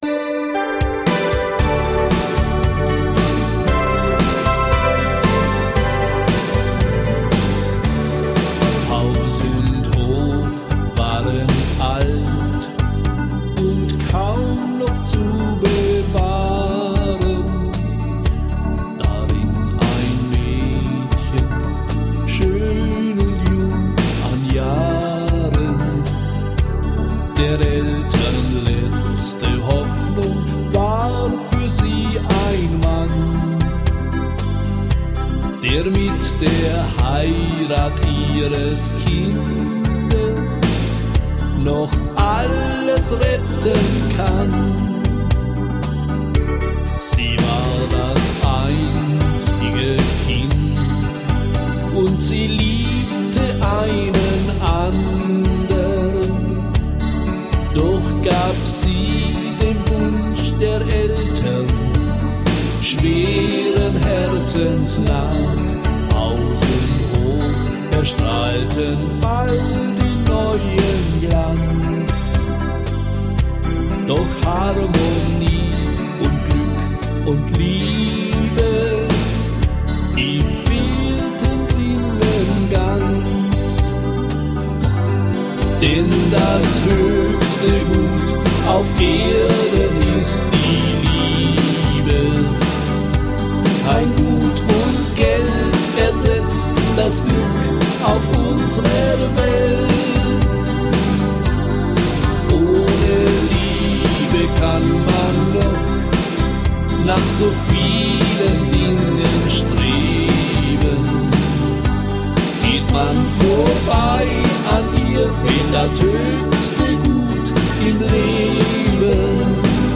Das höchste Gut auf Erden (Volkstümlicher Schlager)